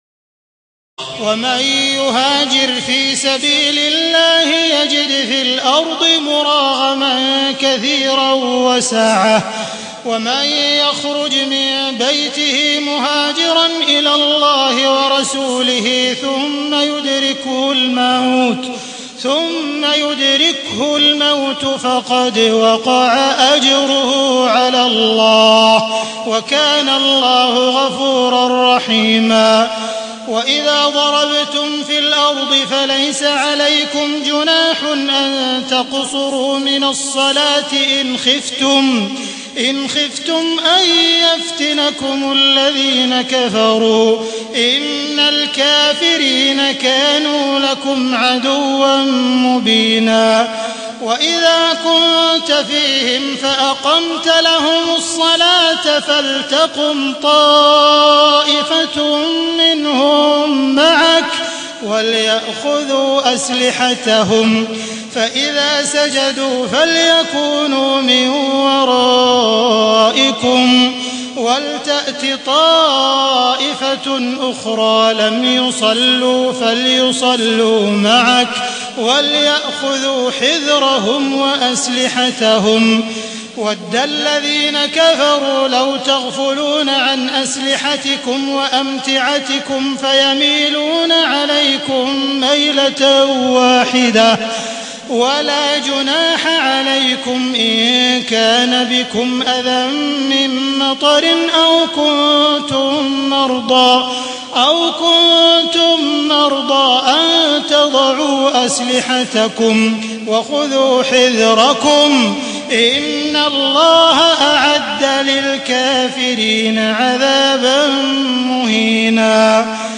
تهجد ليلة 25 رمضان 1432هـ من سورة النساء (100-147) Tahajjud 25 st night Ramadan 1432H from Surah An-Nisaa > تراويح الحرم المكي عام 1432 🕋 > التراويح - تلاوات الحرمين